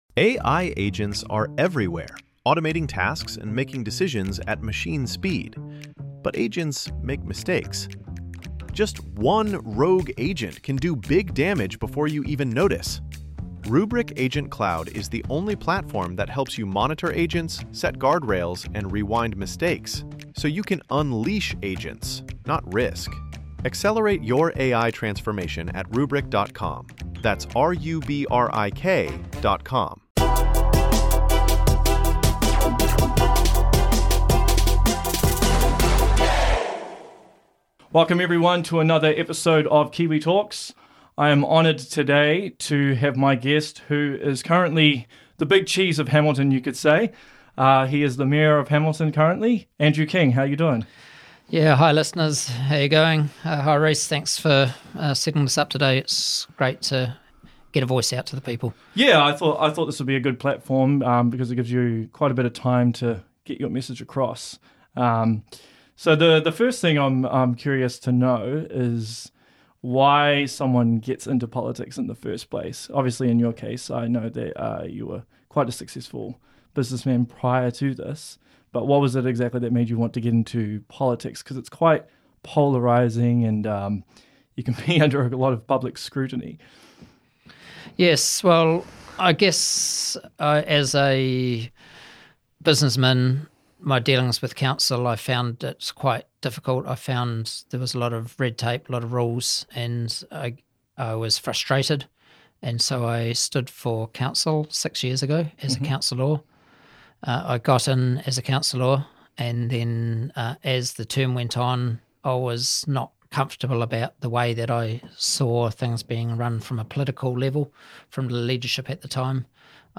#04 - Andrew King Interview (Hamilton Council, Rates, Red Tape, Tourism, Amazon, Low Voter Turnout) ~ Kiwi Talkz Podcast
Spoke with the current mayor of Hamilton Andrew King about various different subjects within the city.